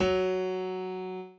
b_pianochord_v100l1o4fp.ogg